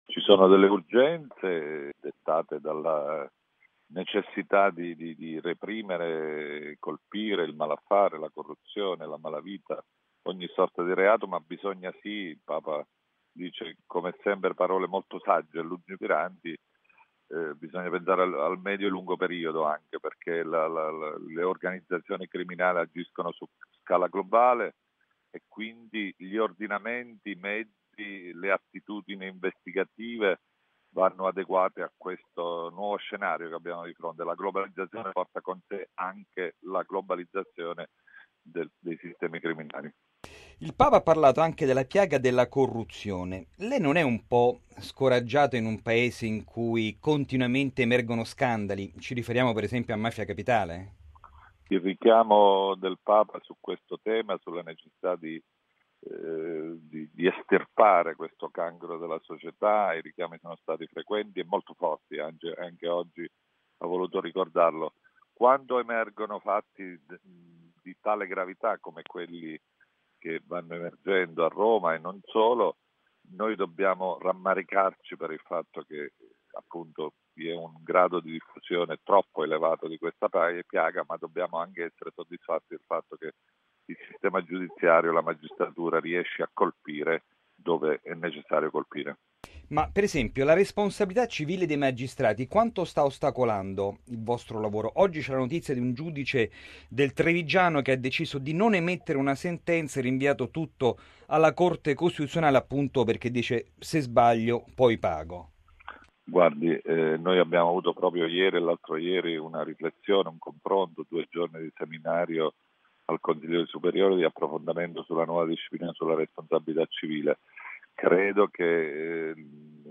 lo ha intervistato: